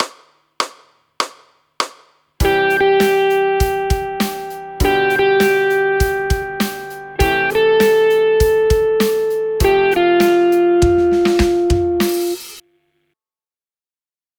We have chosen the I-V-vi-IV progression, which is one of the most popular chord progressions of all time.
The next set of examples plays the scale tone followed by the release, in this case the closest chord tone.
Fifth Resolving to Chord Tone (ex. a)